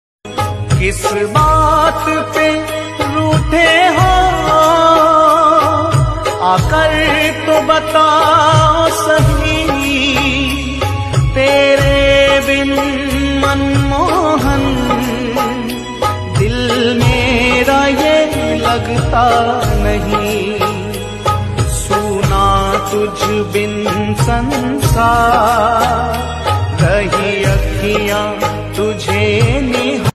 Hindi Songs
• Simple and Lofi sound
• Crisp and clear sound